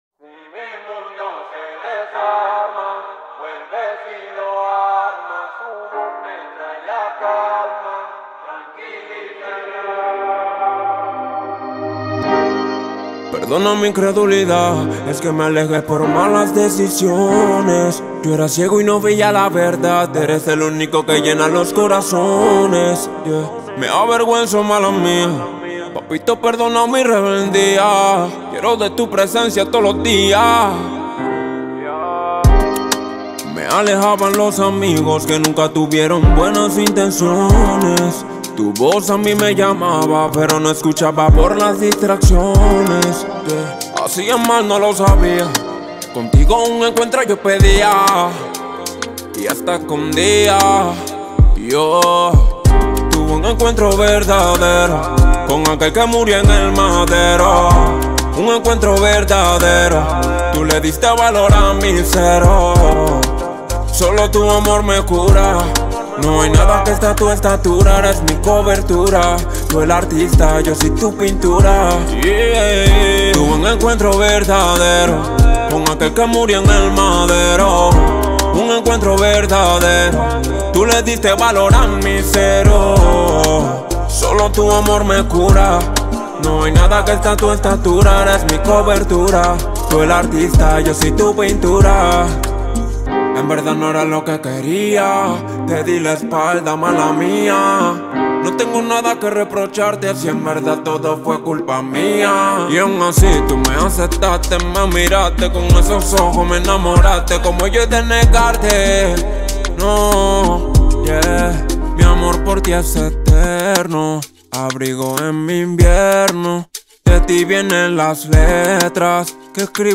сочетая элементы поп и электронной музыки